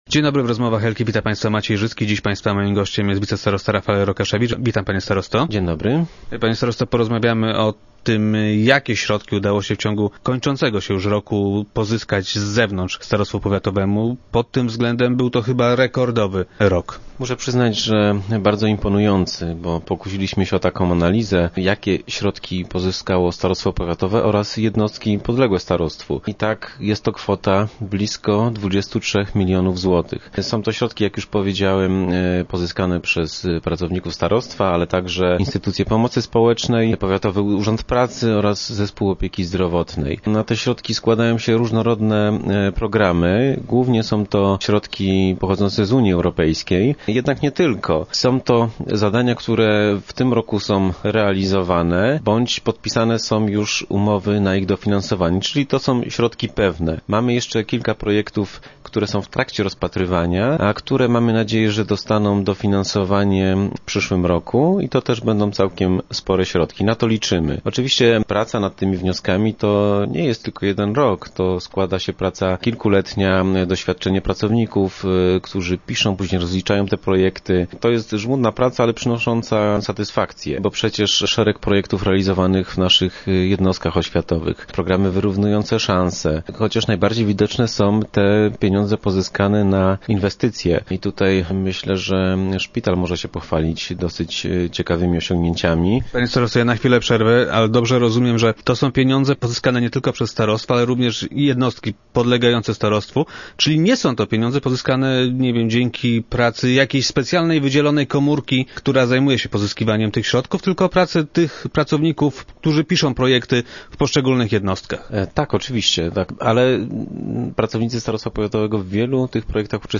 - Głównie są to pieniądze pochodzące z unijnych funduszy, jednak nie tylko. Są to zadania, których realizacja rozpoczęła się w tym roku, lub na których dofinansowanie zostały już podpisane stosowne umowy - informuje wicestarosta Rokaszewicz, który był dziś gościem Rozmów Elki.